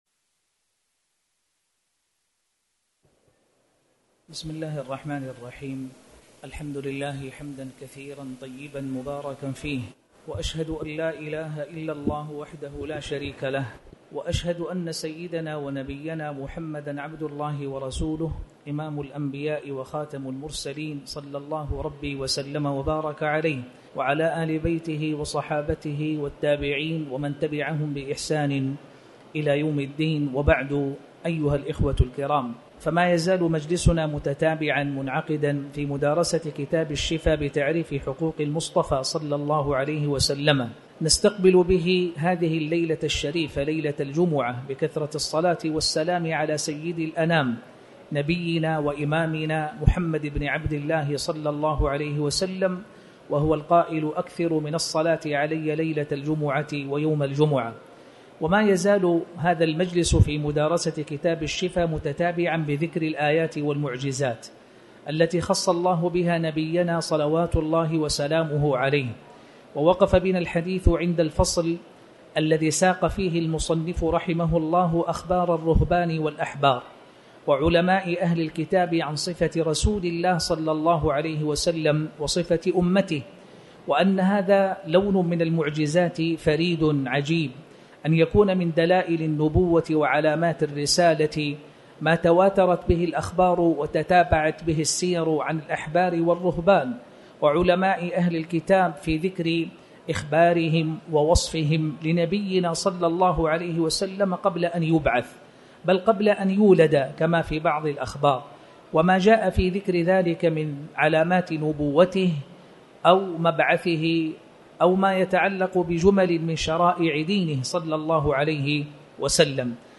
تاريخ النشر ٨ رمضان ١٤٣٩ هـ المكان: المسجد الحرام الشيخ